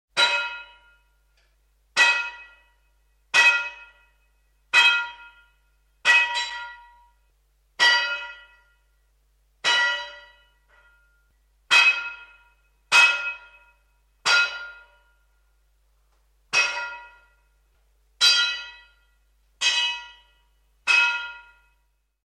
На этой странице собраны звуки кузницы и работы с наковальней: ритмичные удары молота, звон металла, фоновый гул мастерской.
Удары молота по наковальне